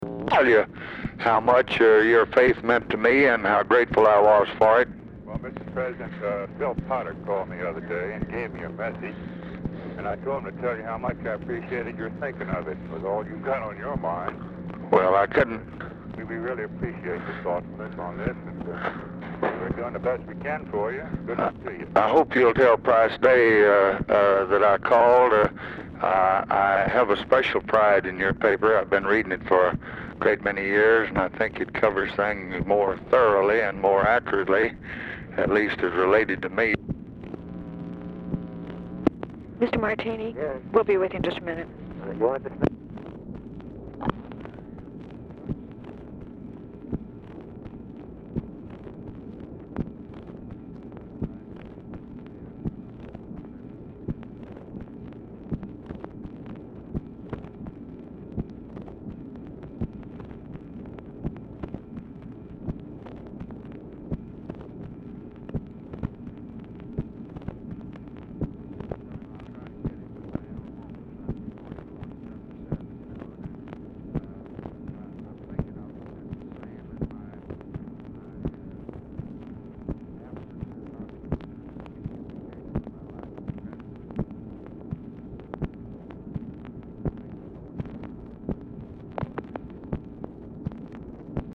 RECORDING STARTS AFTER CONVERSATION HAS BEGUN
REMAINDER OF RECORDING IS INAUDIBLE
Format Dictation belt
Specific Item Type Telephone conversation